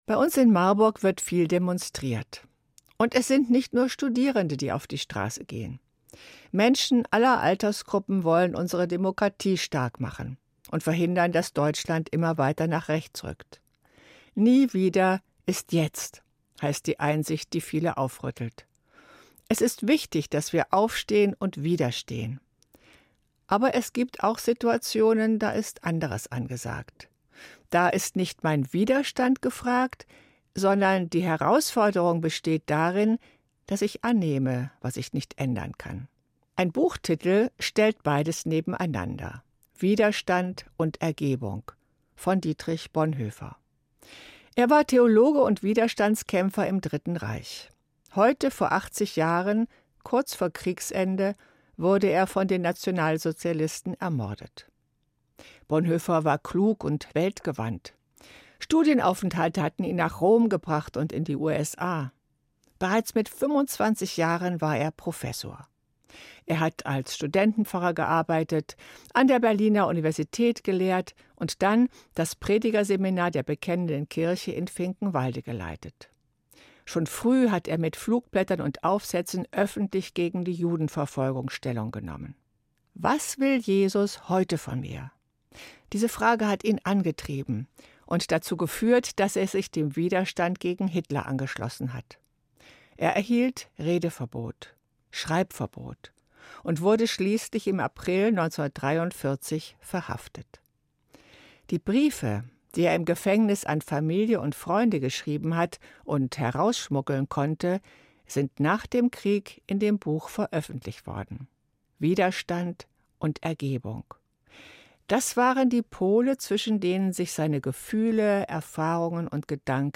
Evangelische Pfarrerin, Marburg